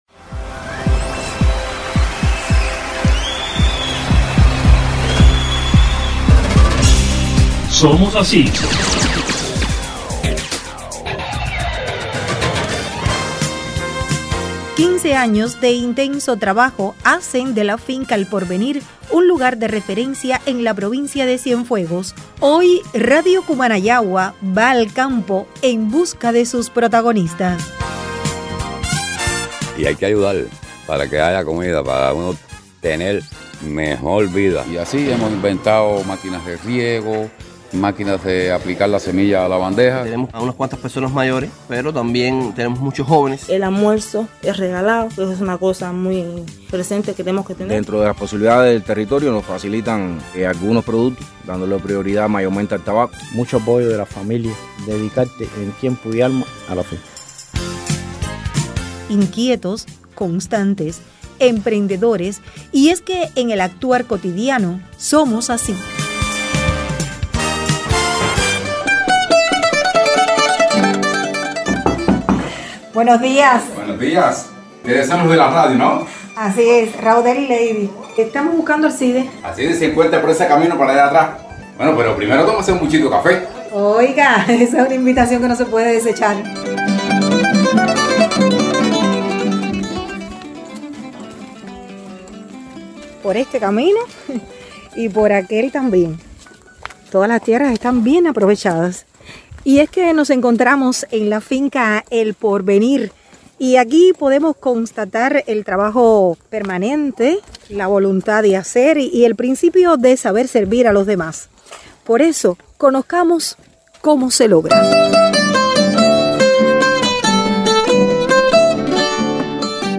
🎧 Programa con participación: “Somos así”, de Radio Cumanayagua